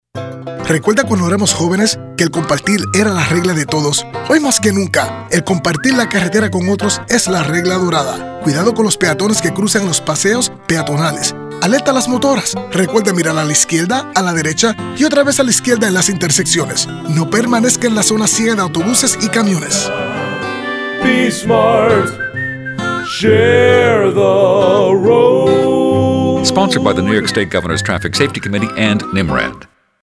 Share the Road :30 Radio PSA.
ShareTheRoadSpanishRadio.mp3